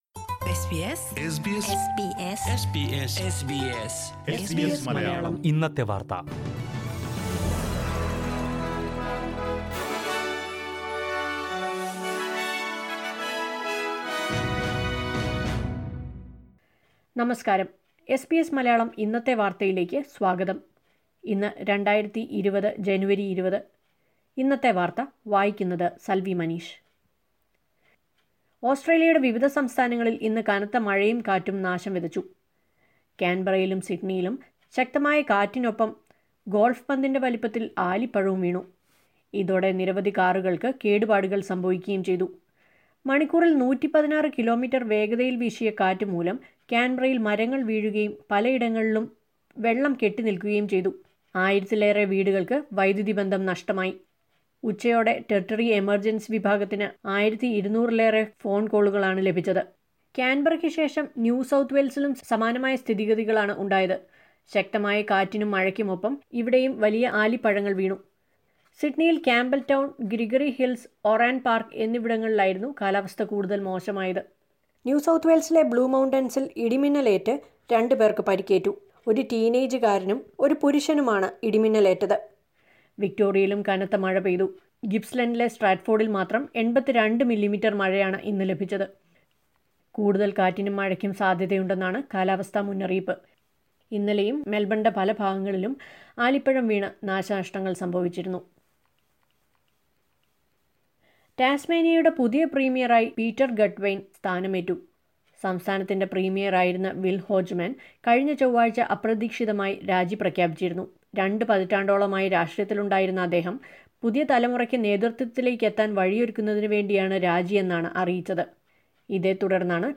2020 ജനുവരി 20ലെ ഓസ്ട്രേലിയയിലെ ഏറ്റവും പ്രധാന വാര്‍ത്തകള്‍ കേള്‍ക്കാം...
news_jan20_1.mp3